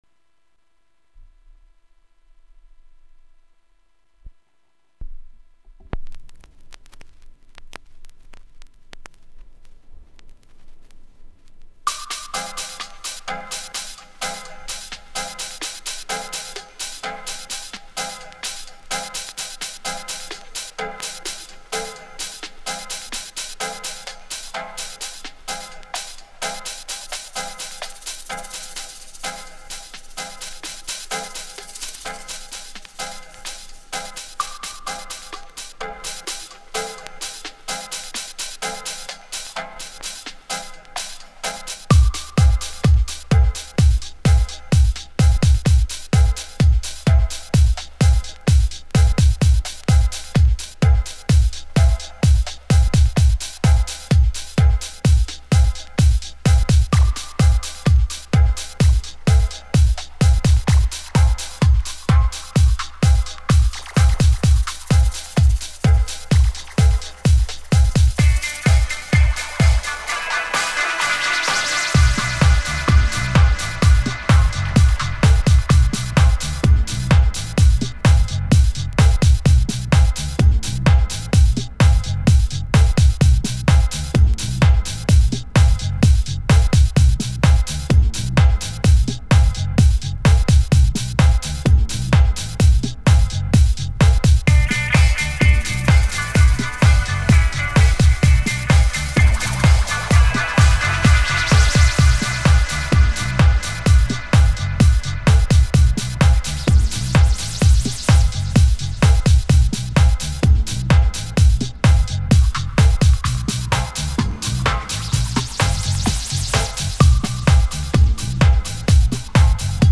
(house) *dj mix*